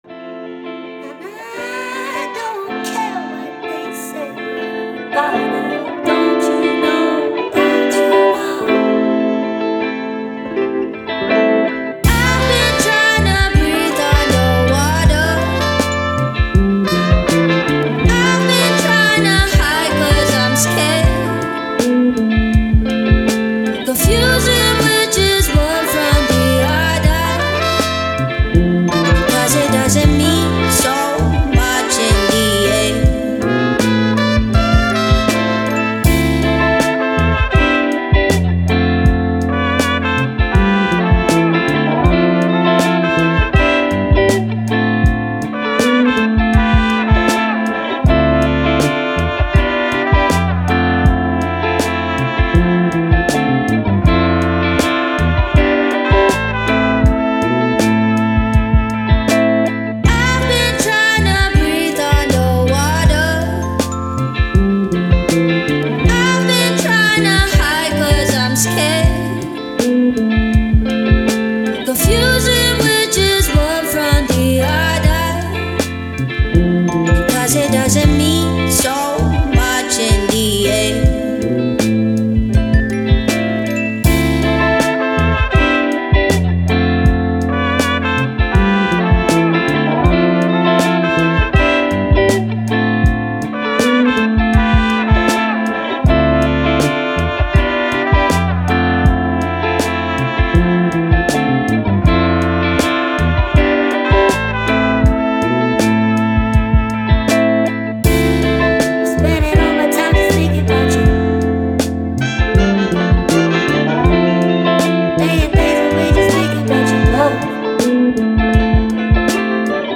Soul, Hip Hop, Vocal, Moving